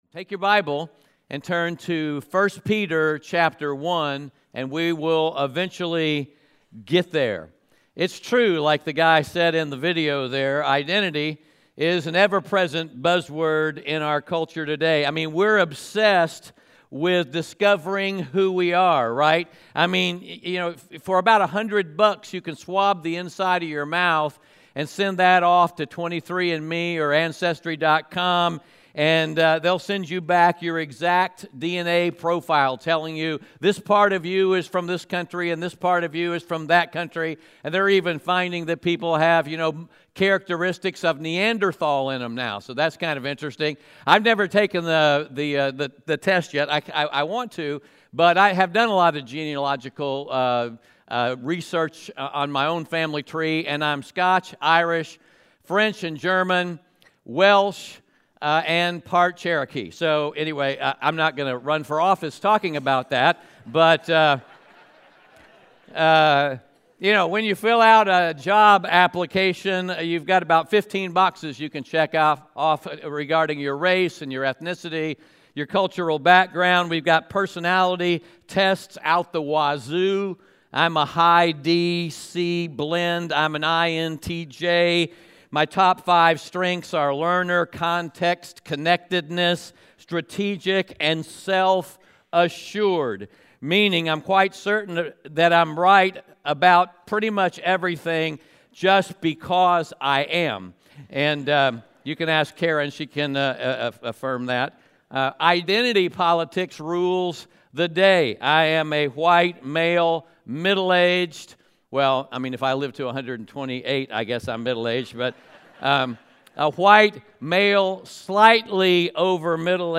1 Peter 1:1-2 Audio Sermon Notes (PDF) Ask a Question Identity is an ever-present buzzword.